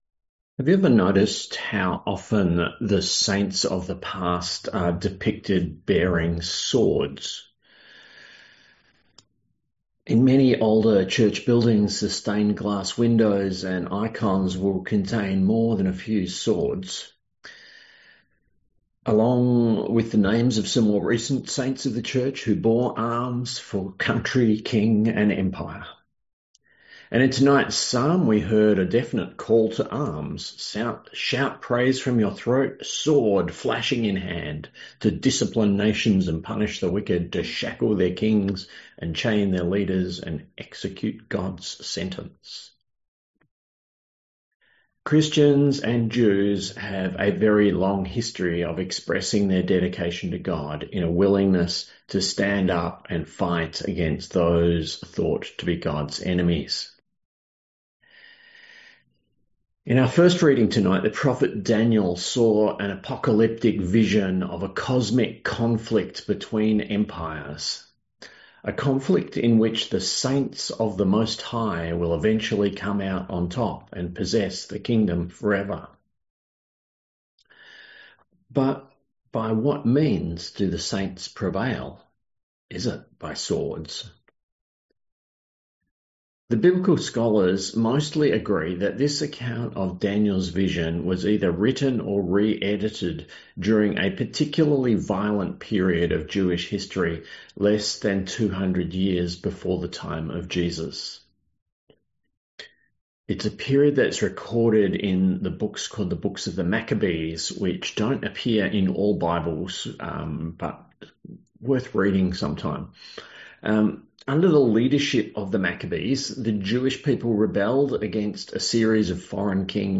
A sermon on Daniel 7:1-18 & Luke 6:20-31